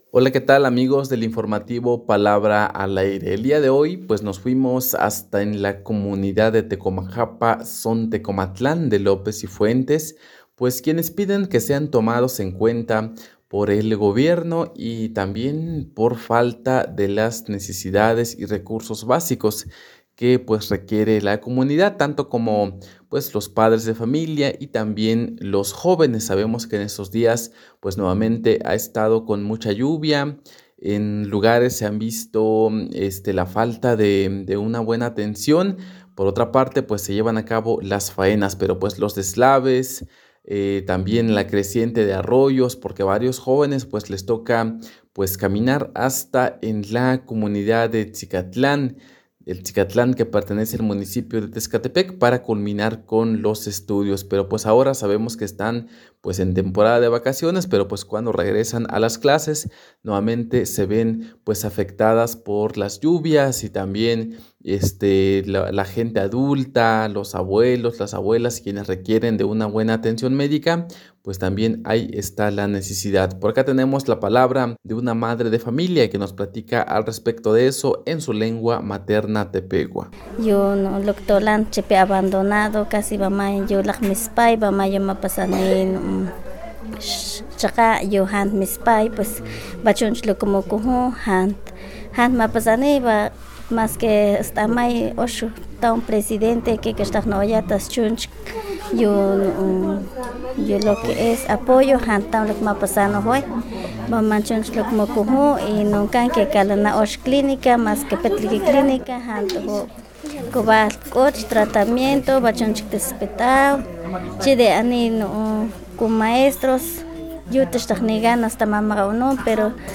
Esto es lo que nos dice una Madre de Familia en la comunidad de Tecomajapa en su lengua materna tepehua.